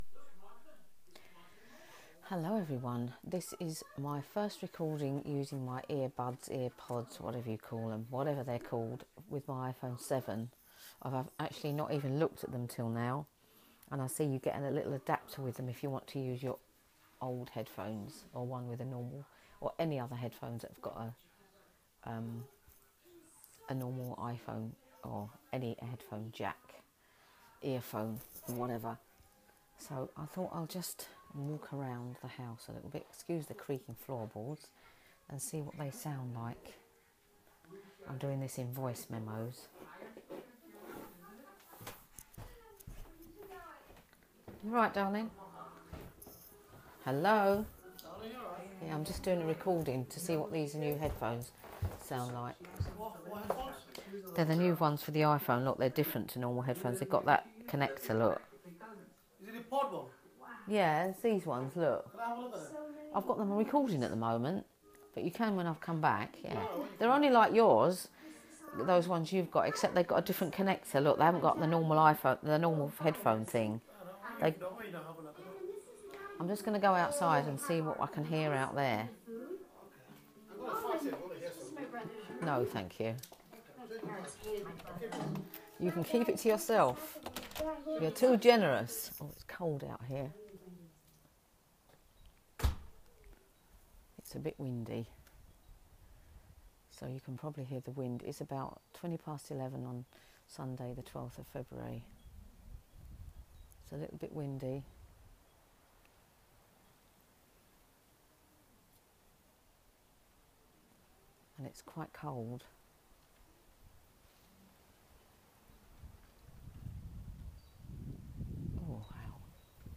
Trying out the earbuds with the iPhone 7, Sunday 12 February 2017